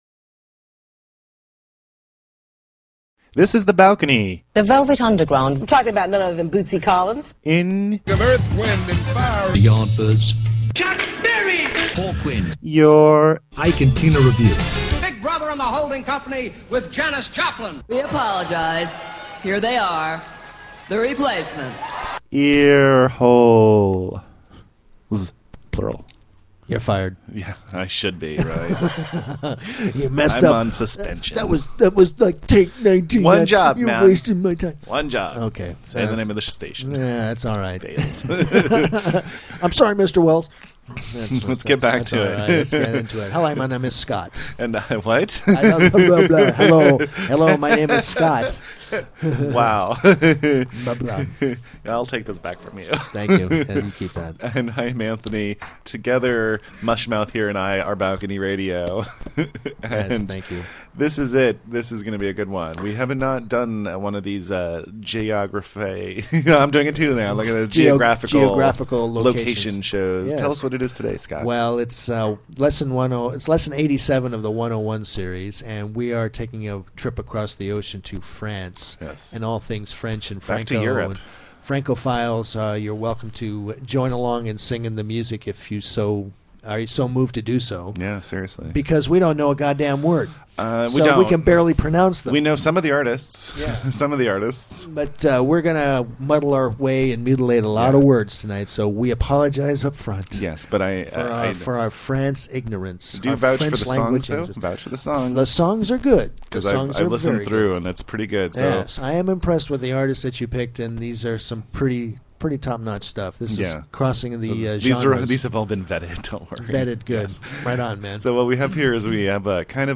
In Lesson 87 of the 101 Series we listen to music from France and make every possible human effort to enunciate the titles of the tunes. We take a chronological journey and start at the origins of French popular music. Our pronunciation does get better towards the end.